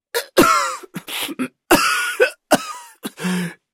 sfx_瞎子咳嗽.ogg